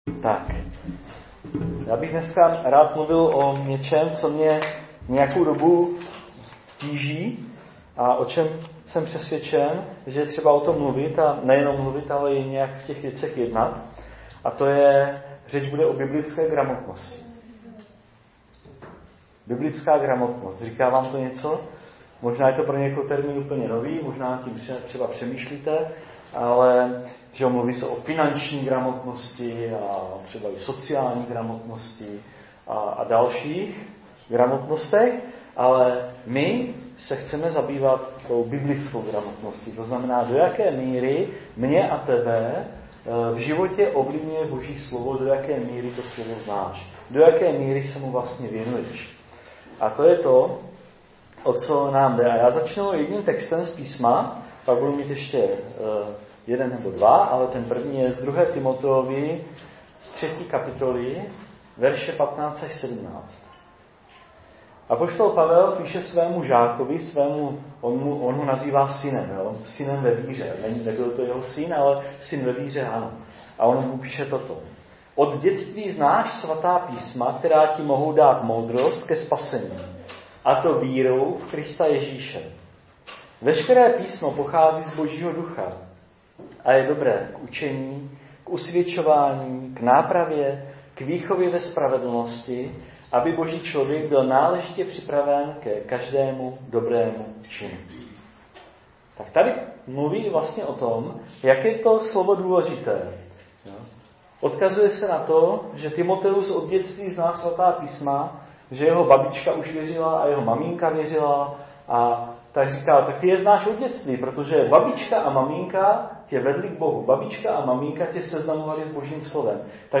Kázání - archiv - 2019